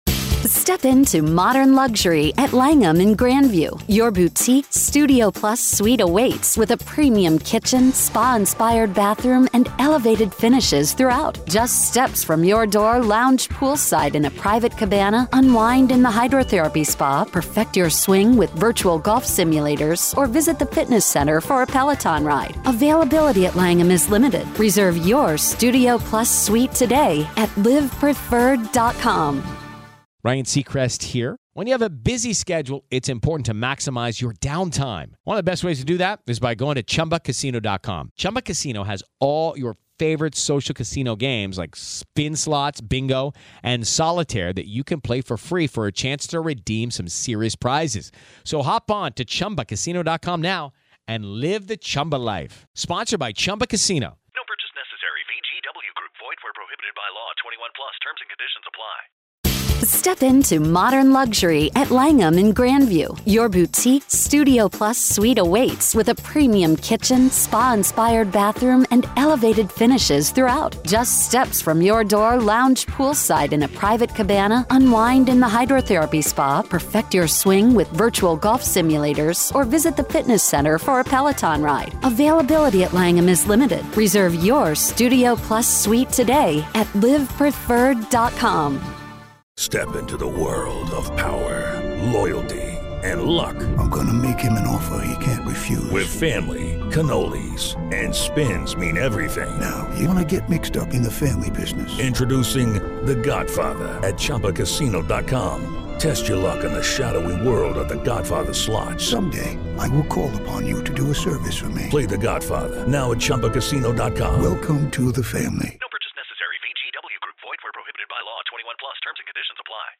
Prosecution Opening